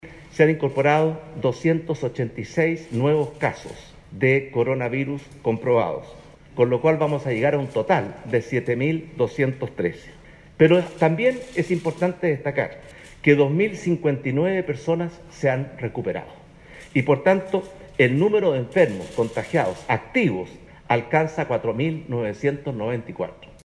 El Presidente Sebastián Piñera adelantó este domingo, durante la inauguración del Hospital Félix Bulnes en la comuna de Cerro Navia, parte de las cifras del balance del avance del coronavirus en el país durante las últimas 24 horas. El mandatario destacó que se sumaron 286 nuevos casos a nivel nacional, con lo que se completan 7.213 desde que se inició la emergencia en Chile, detallando que se han incorporado 286 nuevos casos de coronavirus comprobados.